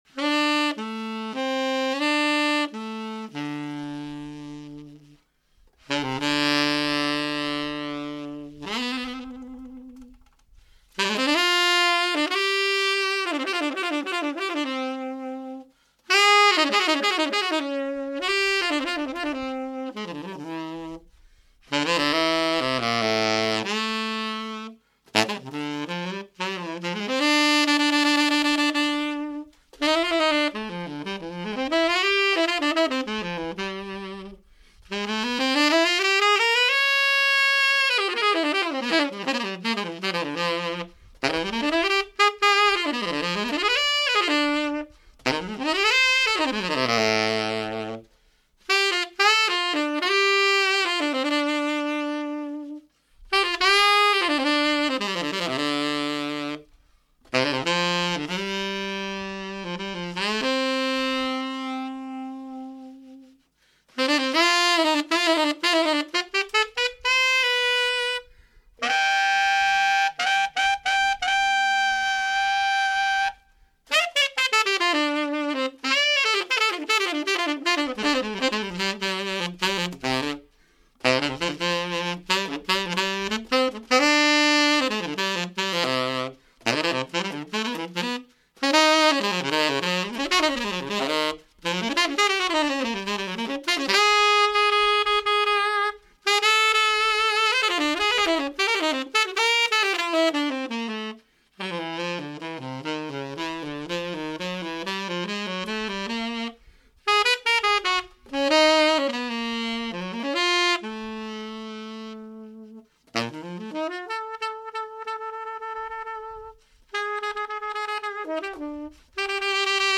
音色と特徴:　ダークで音が太い、輪郭のある音、
スタイル:　ジャズ
Tenor
SepiaJazzTenorMetal.mp3